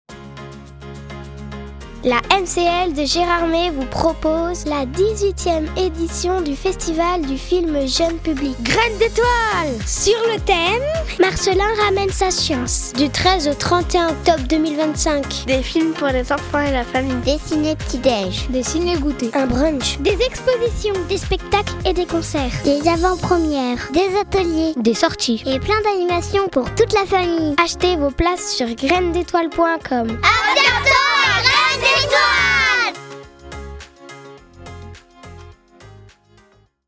La pub radio
Les enfants du centre de loisirs s’emparent de l’atelier radio de la MCL pour enregistrer la bande annonce officielle du festival
Diffusée sur les radios locales partenairesdes Hautes Vosges